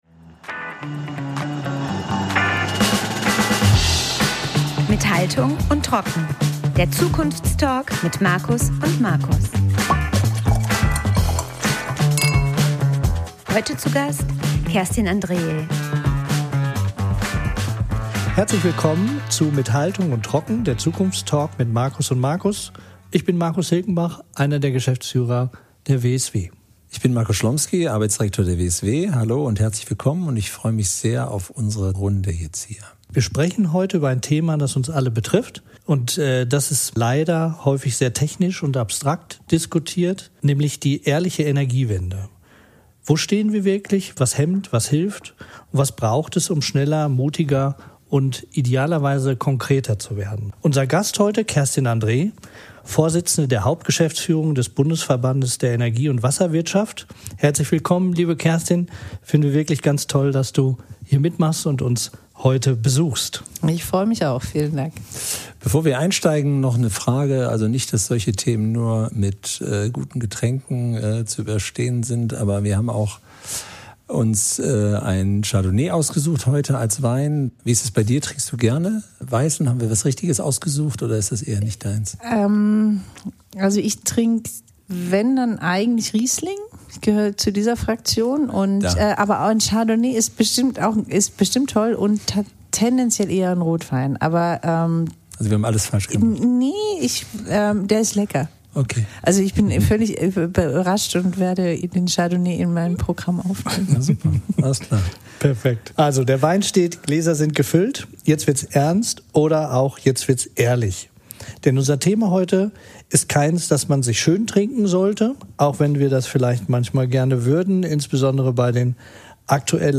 Doch wo stehen wir tatsächlich? Was bremst im System und was könnten wir längst umsetzen, wenn wir konsequenter wären? Unser Gast Kerstin Andreae (BDEW) bringt klare Worte und langjährige Erfahrung aus Energiepolitik, Verbandsarbeit und Branchensteuerung mit.